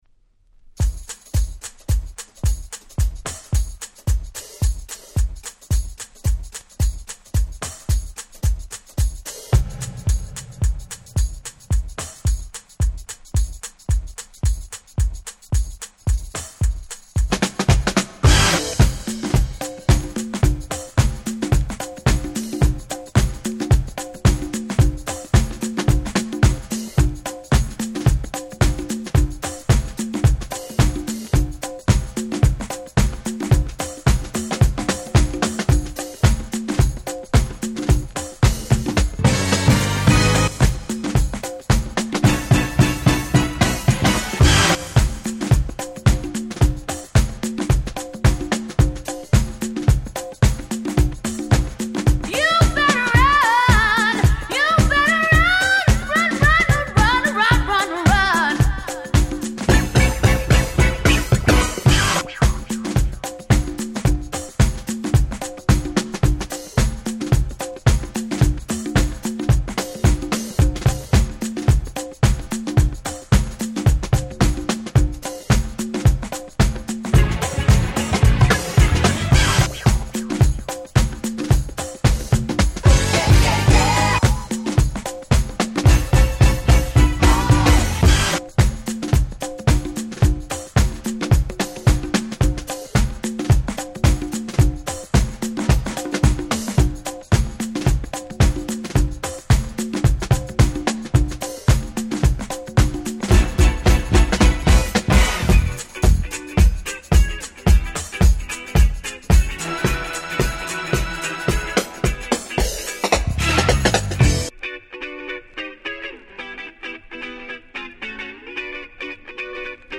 原曲に大変忠実、原曲の良さを一切損なっておりません！